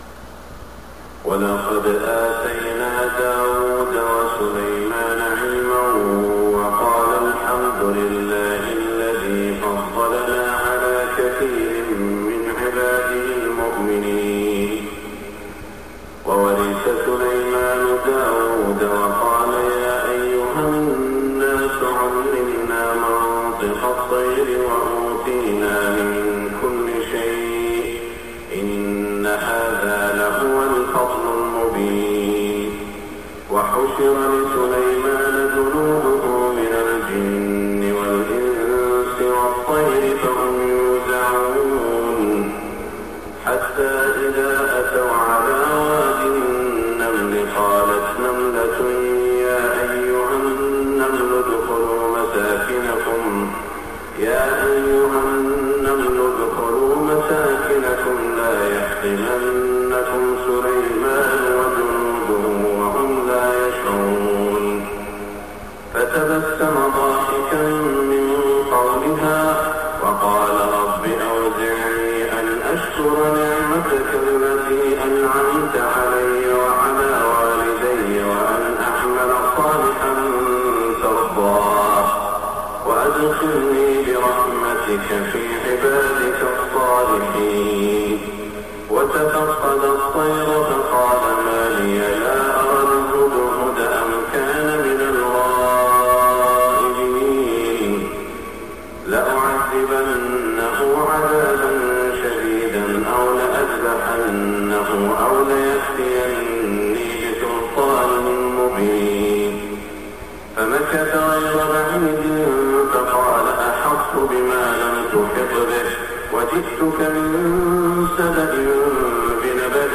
صلاة الفجر 9-3-1426 من سورة النمل > 1426 🕋 > الفروض - تلاوات الحرمين